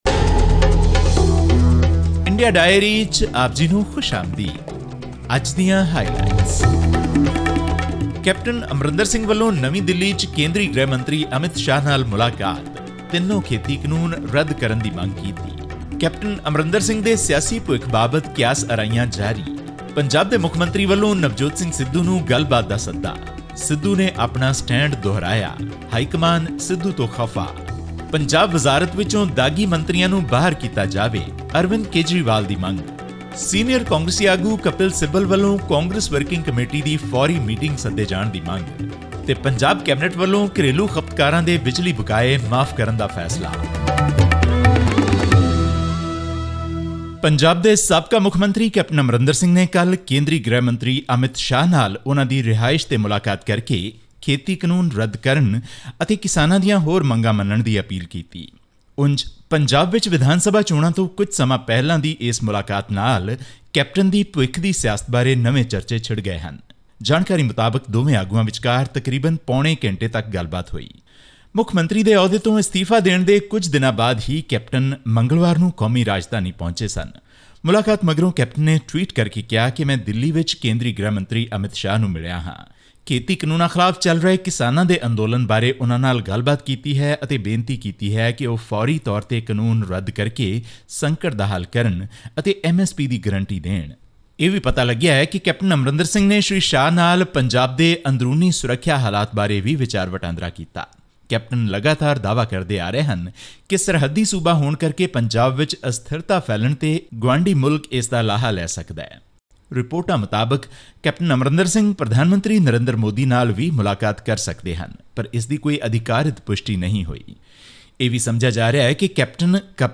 Targetting the ongoing Congress turmoil in Punjab, Aam Aadmi Party's national convener and Delhi Chief Minister Arvind Kejriwal said his party has come up with a detailed plan for the state's development while other parties are busy dealing with crises. This and more in our weekly news update from India.